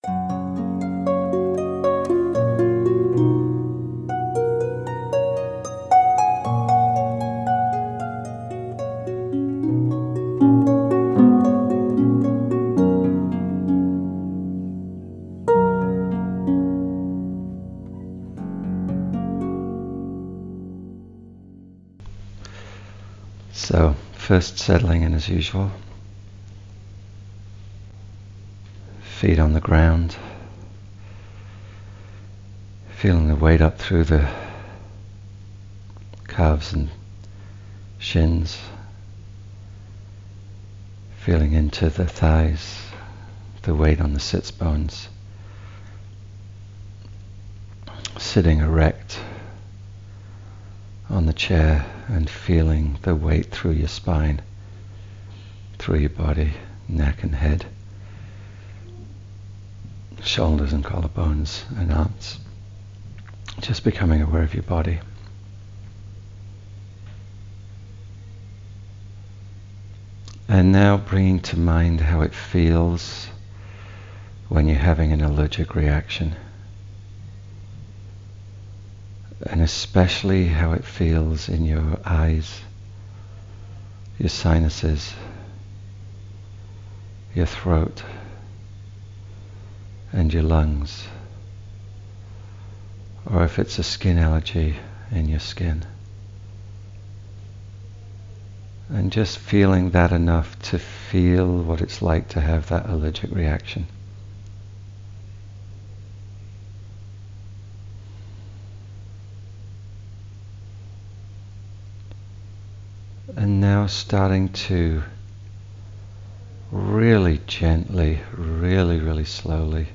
Follow the Guided Exploration Download After The Exploration Once you have completed the exploration, it’s important that you write down your experiences as soon as you have finished.